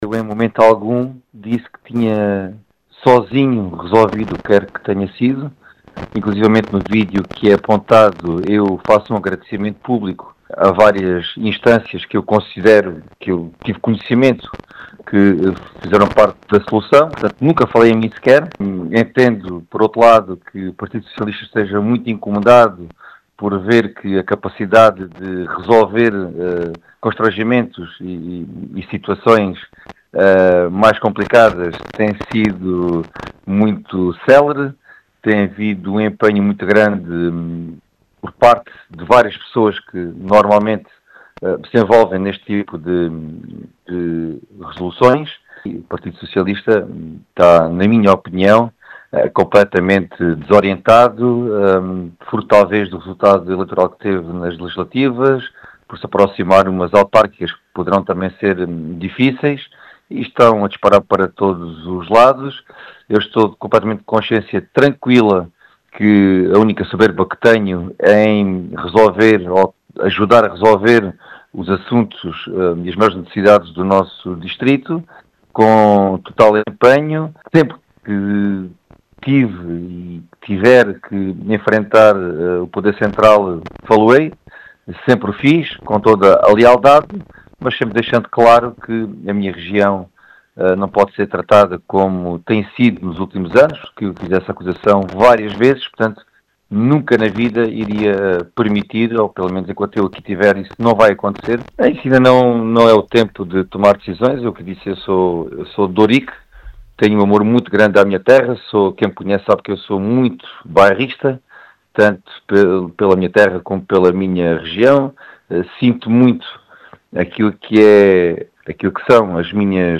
As explicações foram deixadas por Gonçalo Valente, deputado eleito pela AD, no distrito de Beja, aos microfones da Rádio Vidigueira.